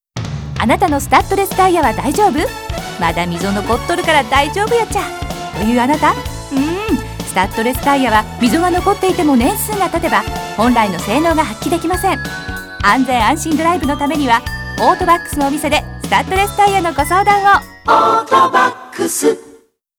CMのナレーション